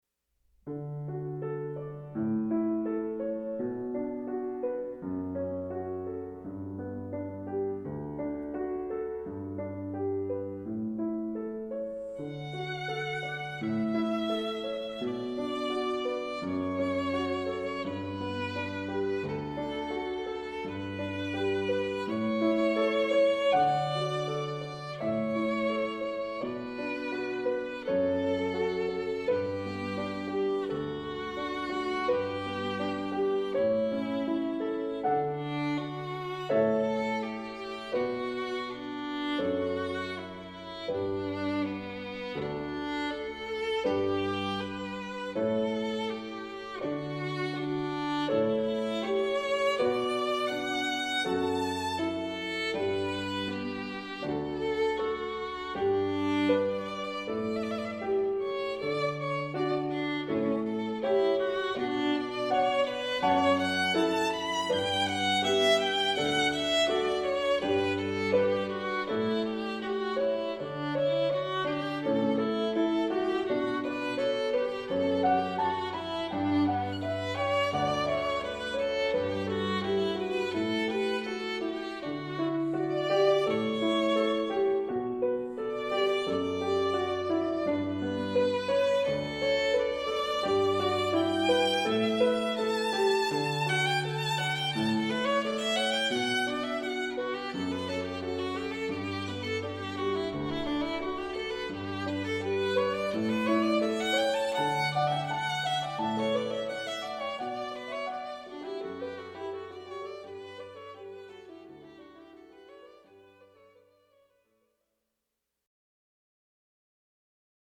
Voicing: Viola and Piano